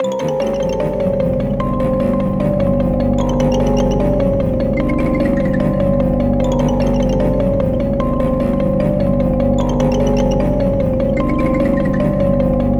HORROR MUSIC BOX
A Broken Toy_Cello In.wav